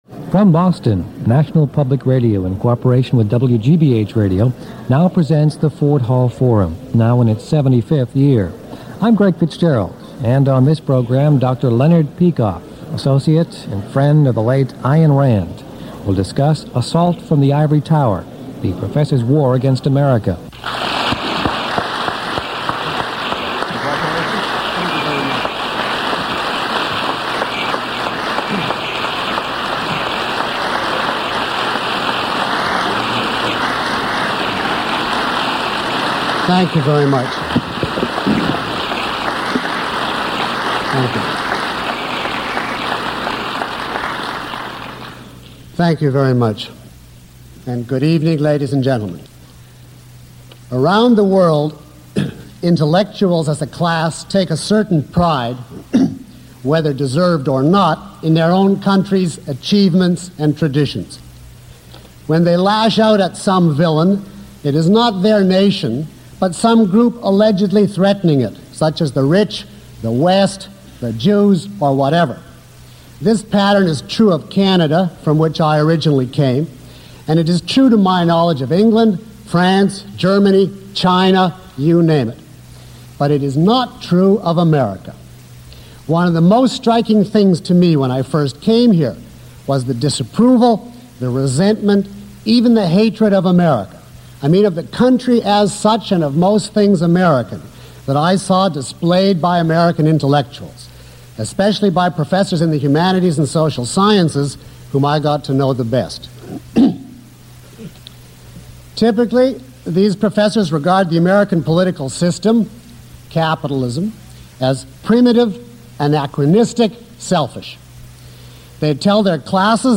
Lecture (MP3) Questions about this audio?
Download Study Guide (PDF) Q&A Guide Below is a list of questions from the audience taken from this lecture, along with (approximate) time stamps. 54:55 Is it a part of the philosophy of Objectivism to not give any kind of support to the Soviet Union?